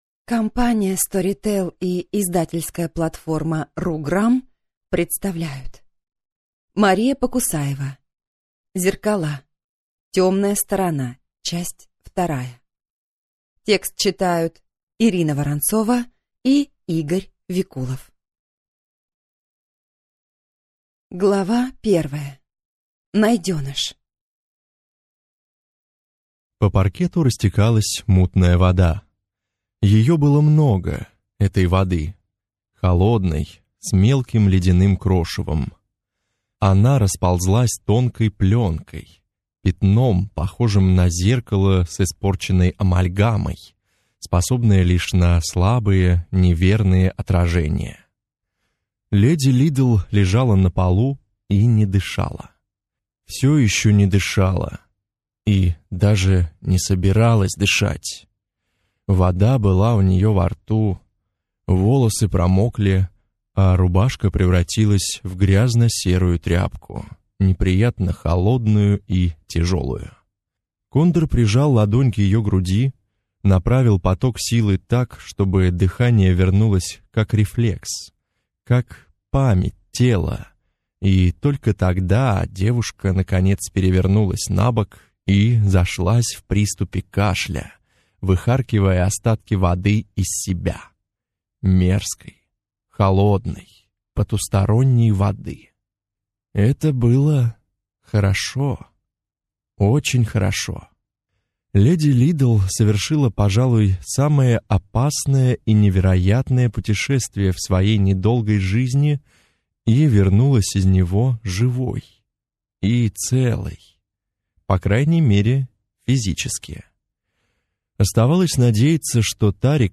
Аудиокнига Зеркала. Темная сторона | Библиотека аудиокниг